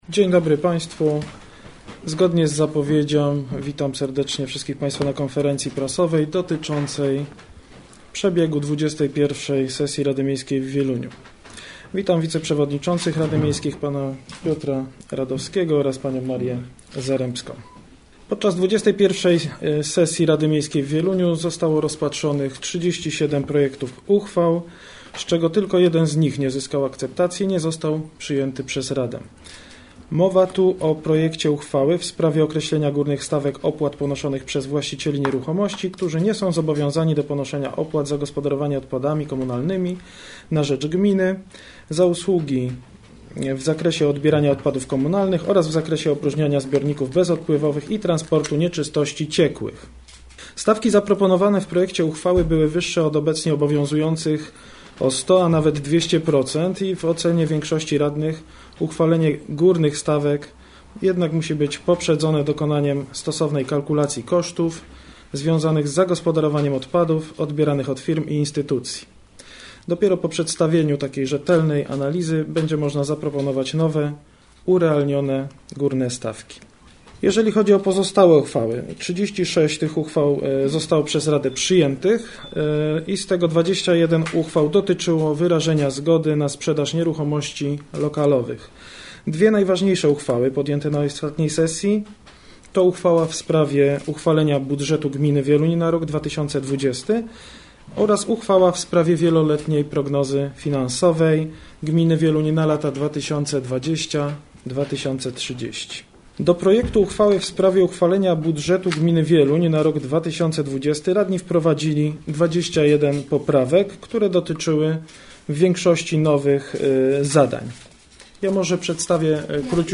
Dziś w sali 11 Urzędu Miejskiego w Wieluniu odbyła się druga konferencja prasowa zwołana przez przewodniczącego Rady Miejskiej, Tomasza Akulicza,
Więcej w pełnym zapisie audio całej konferencji do odsłuchania poniżej: